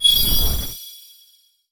magic_flame_of_light_05.wav